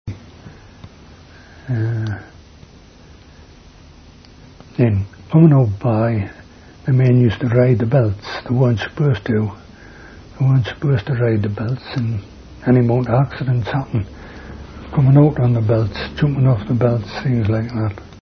Woodhorn